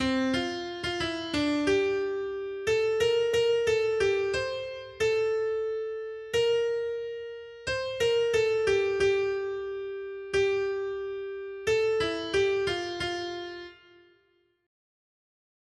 Noty Štítky, zpěvníky ol540.pdf responsoriální žalm Žaltář (Olejník) 540 Skrýt akordy R: Pro slávu svého jména vysvoboď nás, Pane! 1.